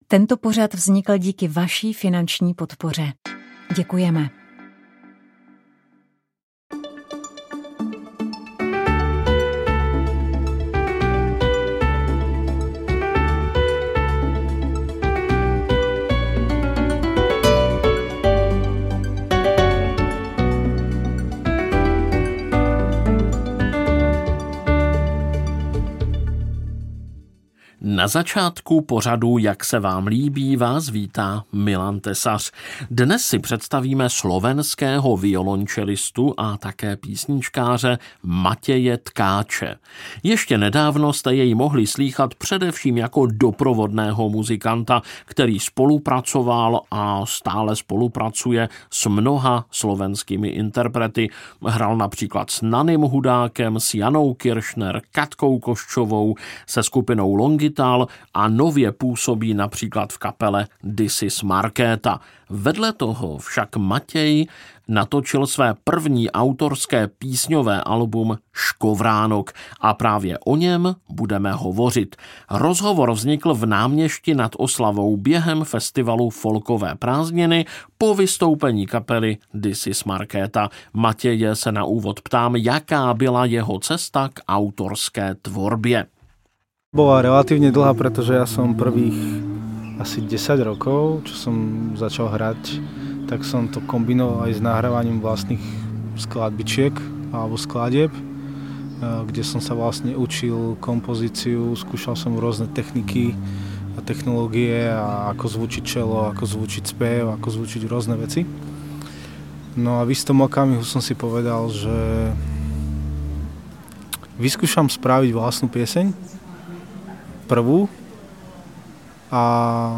zpěváka a kytaristy
v bohatých aranžích